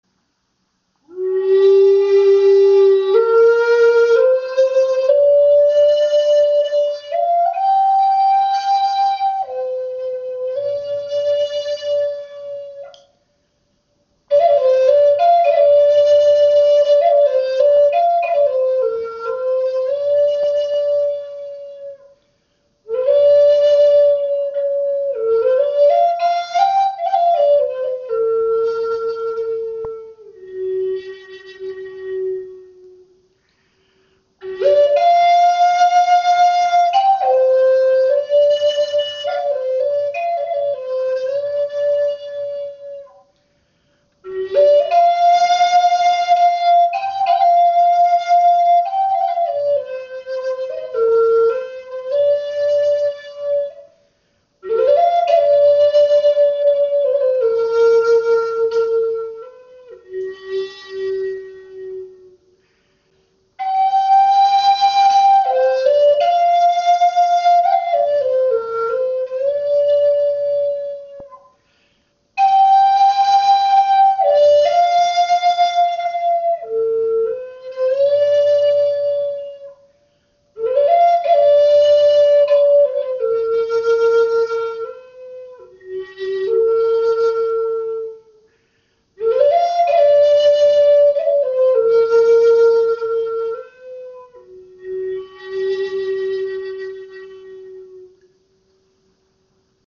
Klangbeispiel
Diese Gebetsflöte in G ist auf 432 Hz gestimmt. Sie wurde aus Wengeholz geschaffen und abschliessend geölt, so dass sie ein seidenglänzendes Finish hat.